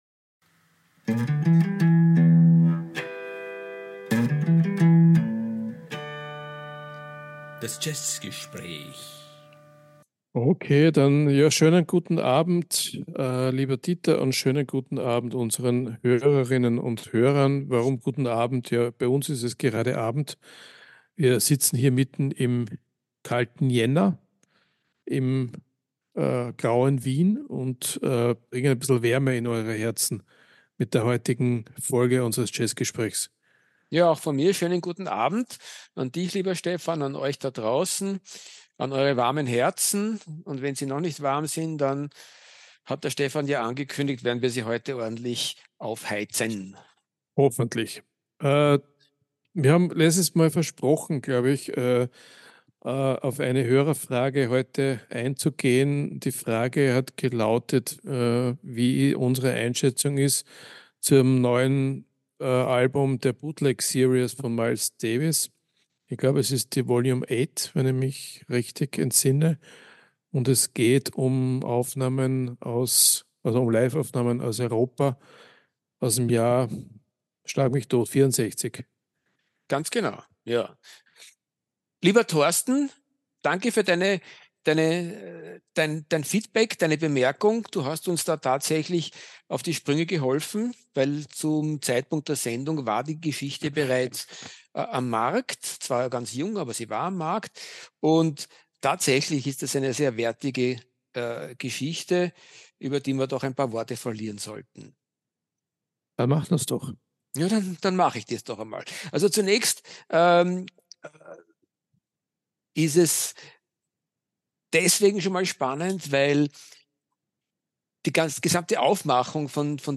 Das ist natürlich ein Jazzgespräch über den großen Duke Ellington und sein unermessliches Schaffen im US-amerikanischen Jazz des 20. Jahrhunderts.